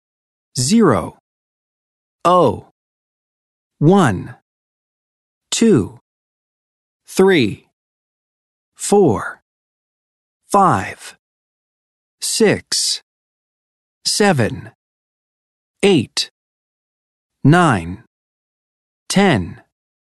Às vezes, ao falar números de telefone ou endereço, por exemplo, não dizemos ZERO e sim OH.
Observe mais uma vez a pronuncia dos números de 0 a 10: